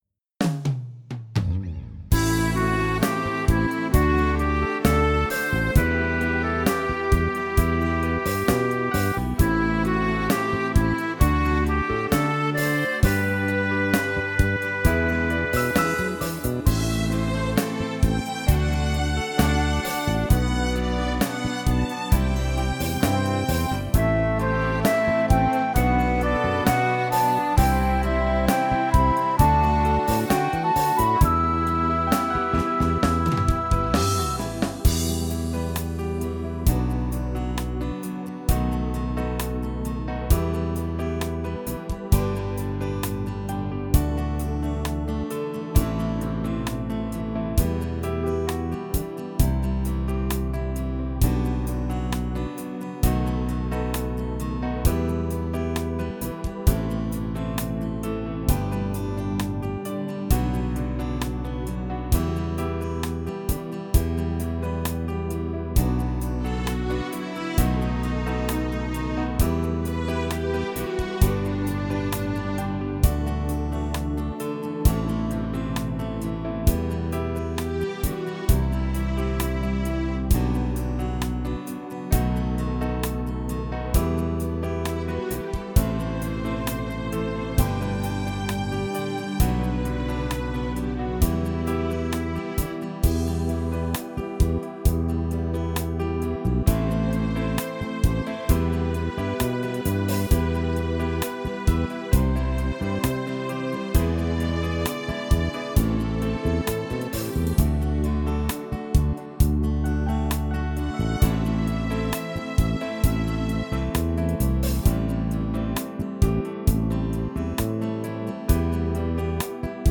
הפלייבק המשופר